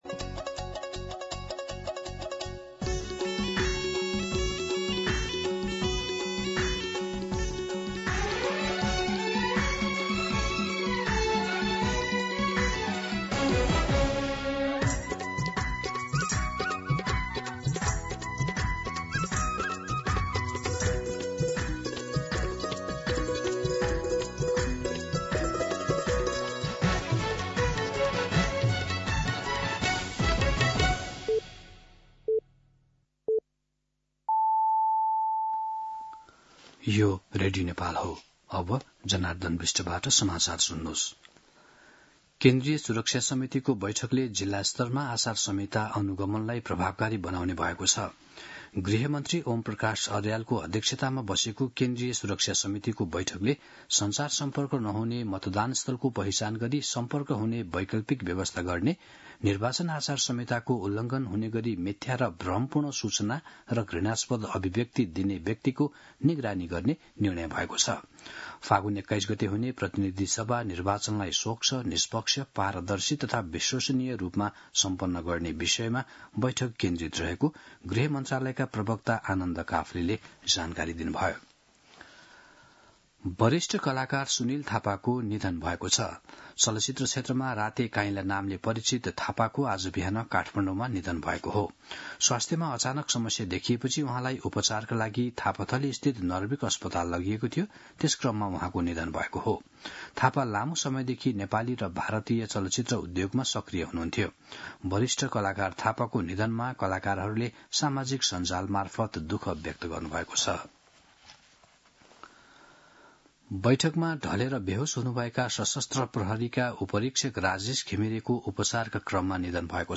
मध्यान्ह १२ बजेको नेपाली समाचार : २४ माघ , २०८२
12-pm-Nepali-News-1.mp3